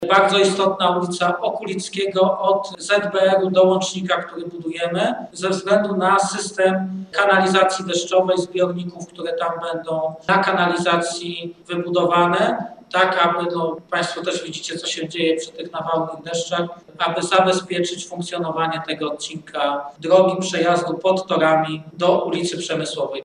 Mówił o tym na ostatniej sesji prezydent Stalowej Woli Lucjusz Nadbereżny: